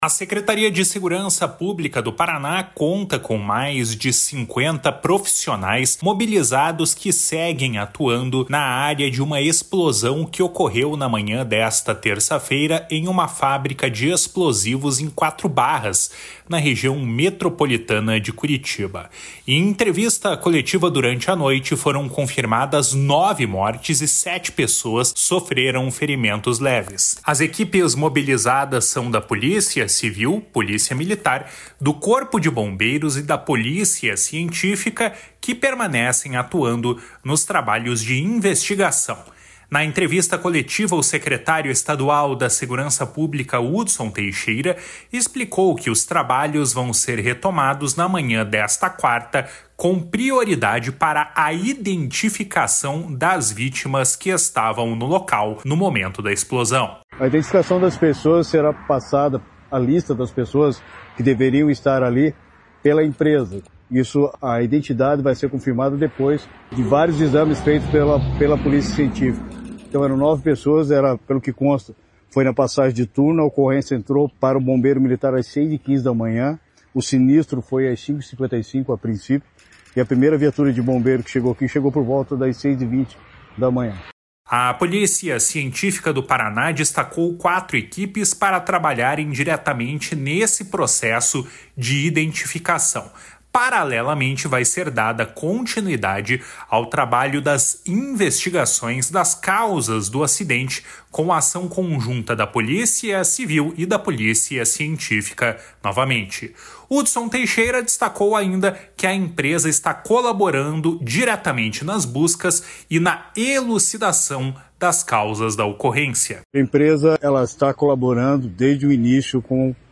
// SONORA HUDSON TEIXEIRA //